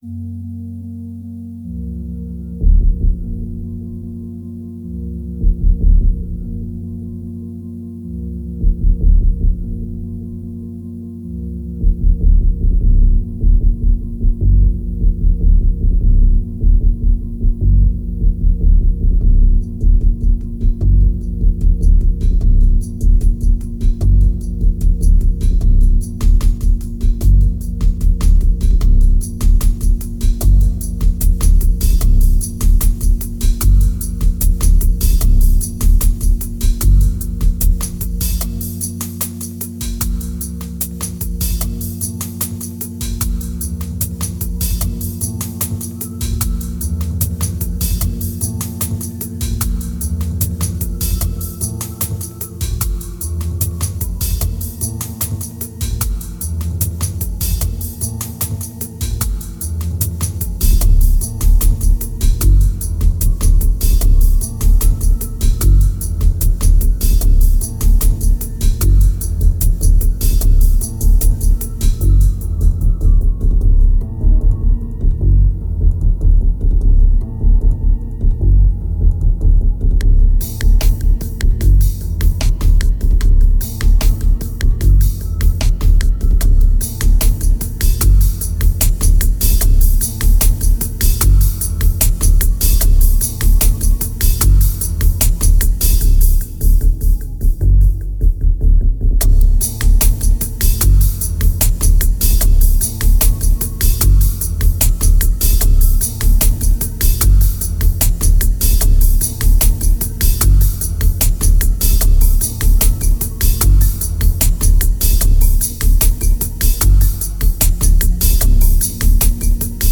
2380📈 - -40%🤔 - 75BPM🔊 - 2010-03-02📅 - -309🌟